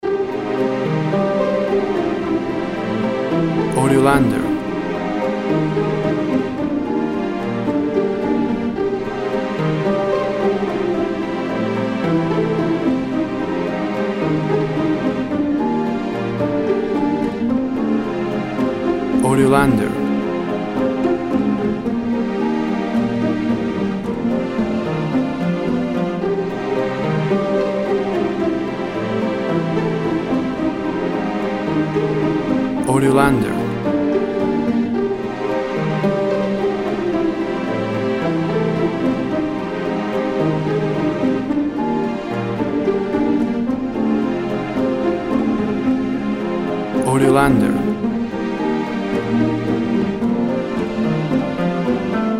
Tempo (BPM) 111